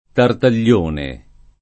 [ tartal’l’ 1 ne ]